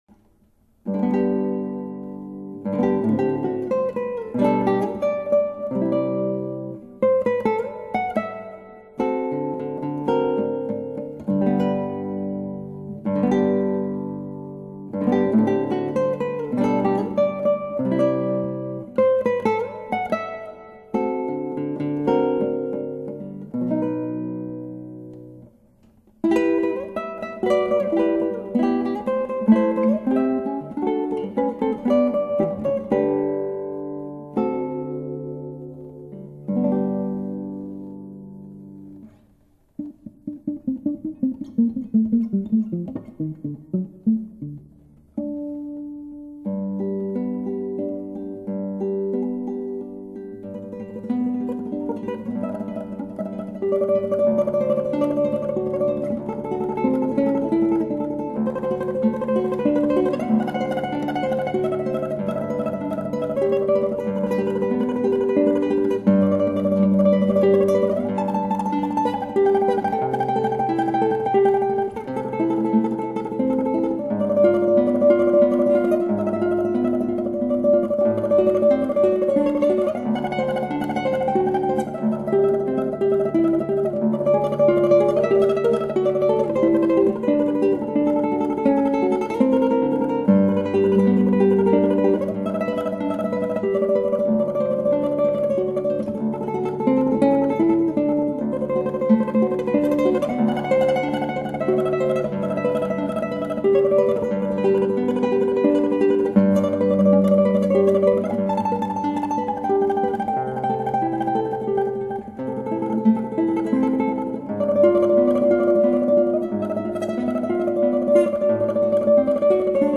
クラシックギター 「森に夢見る現状報告」 編
ギターの自演をストリーミングで提供
トレモロ最初のとこ、調子でなくて最初から挫折気味です。 また途中忘れが入ってどうしようかと・・・強引に弾ききりましたが。 「森に夢見る」